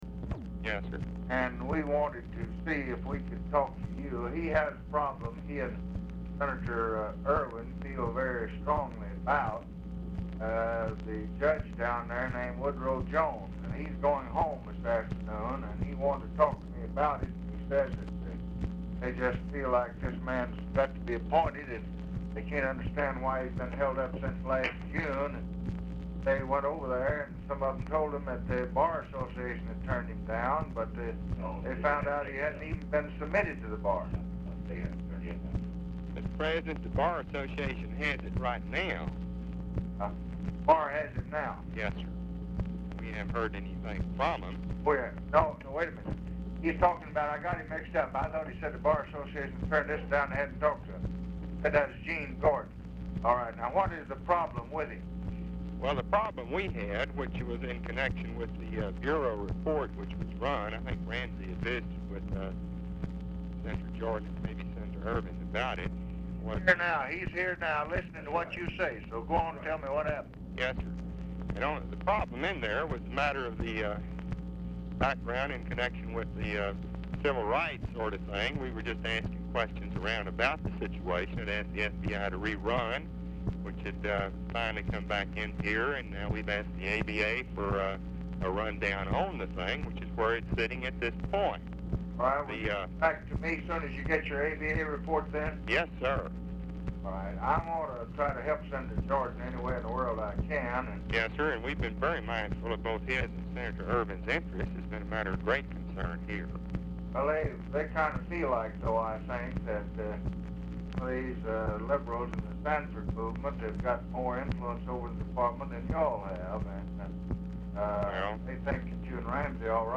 Telephone conversation # 11551, sound recording, LBJ and BAREFOOT SANDERS, 2/16/1967, 1:25PM | Discover LBJ
LBJ APPARENTLY ON SPEAKERPHONE; RECORDING STARTS AFTER CONVERSATION HAS BEGUN
Format Dictation belt
Location Of Speaker 1 Oval Office or unknown location